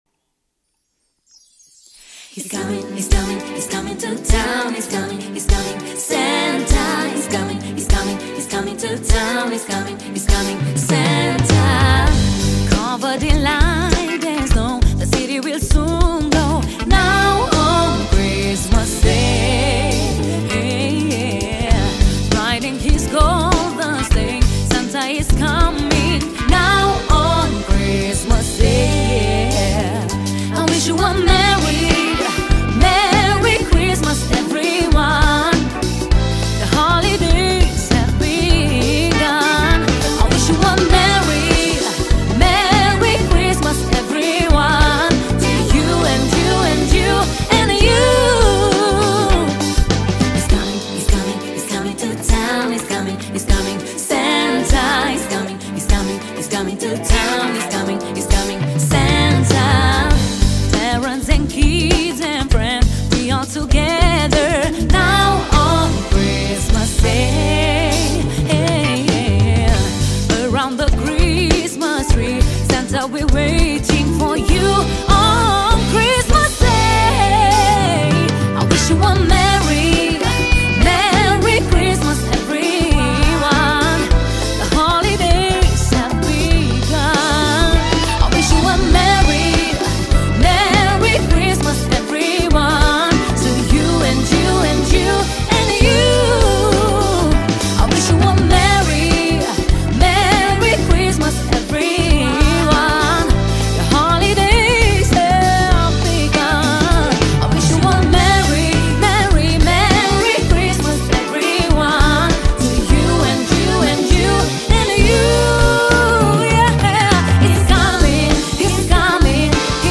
Data: 11.10.2024  Colinde Craciun Hits: 0